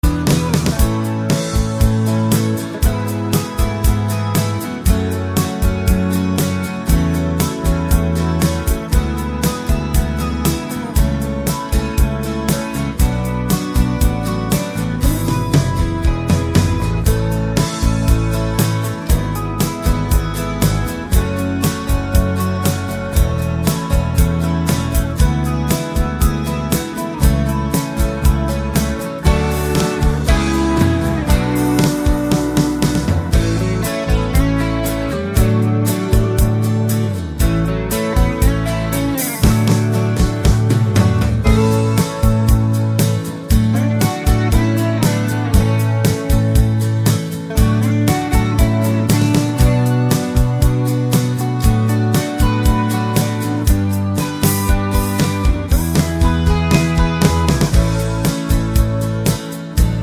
no Backing Vocals Rock 4:56 Buy £1.50